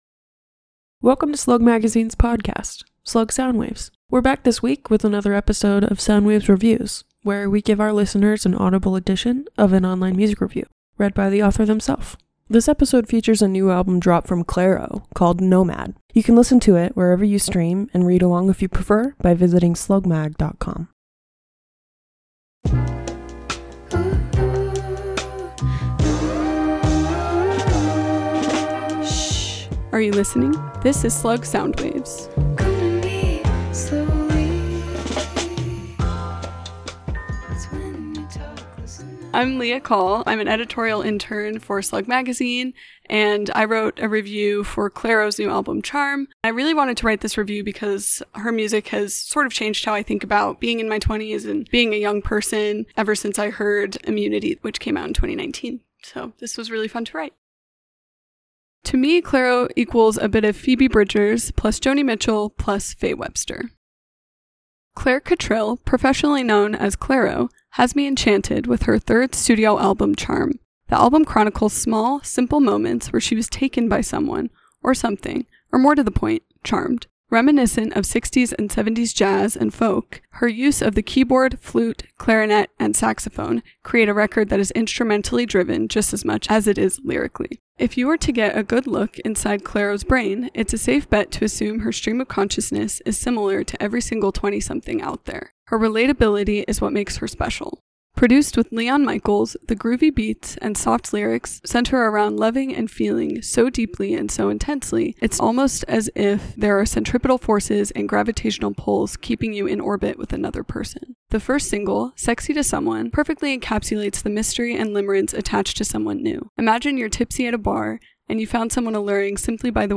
reads her review of Clairo's newest album Charm.